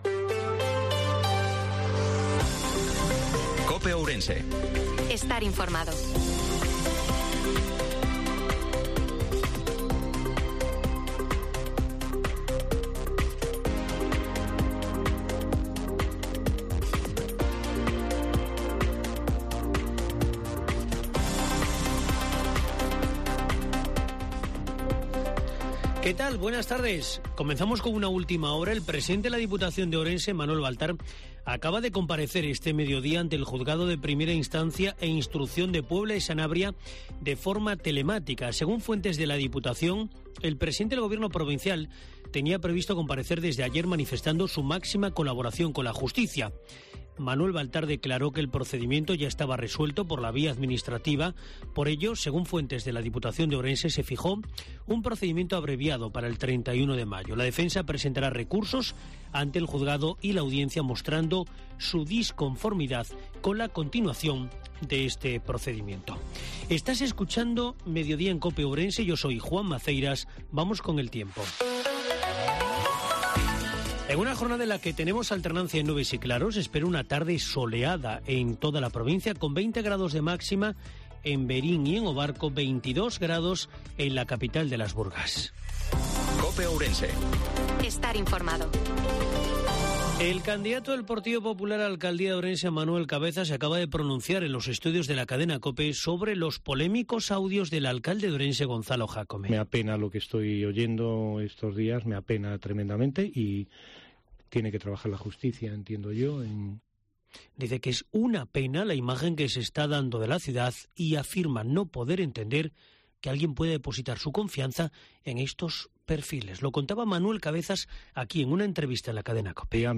INFORMATIVO MEDIODIA COPE OURENSE-11/05/2023